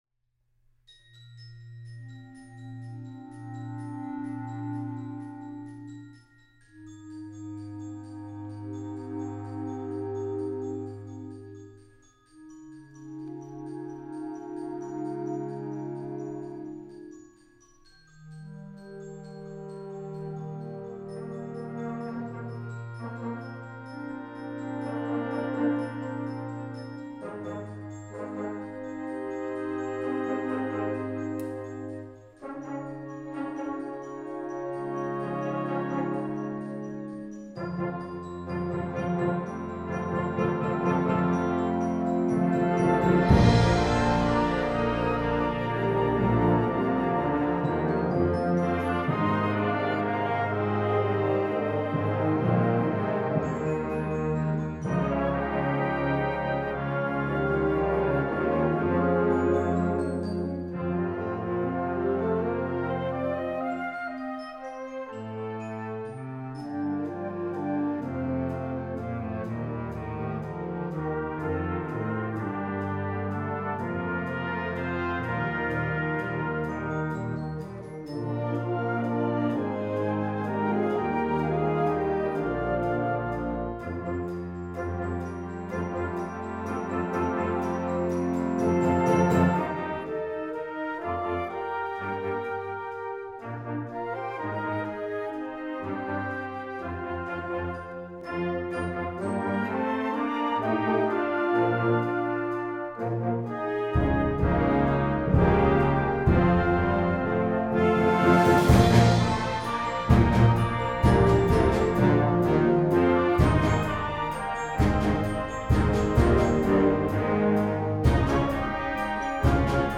Genre: Band
Genre: Band | # of Players: Standard